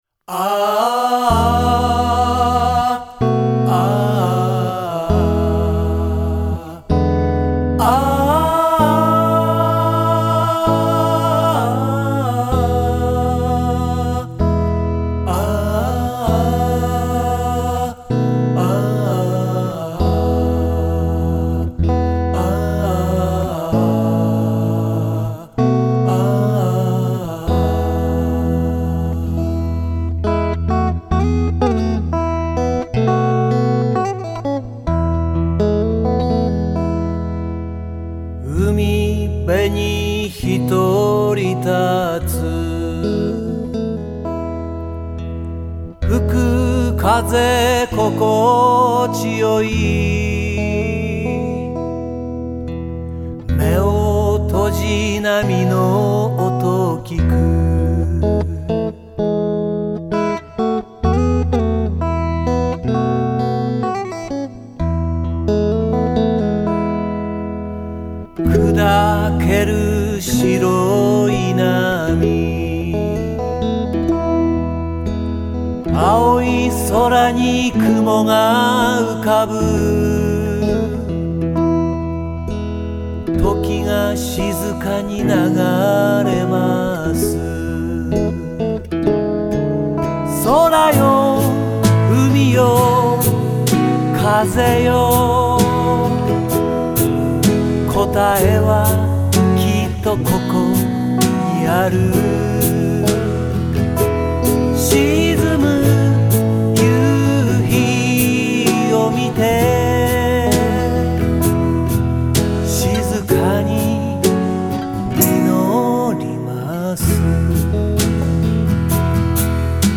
EG：ベスタグラハムストラトキャスター×２
Mini guiter：ヤマハギタレレ
DR：ヤマハDR55
８ｃｈ使用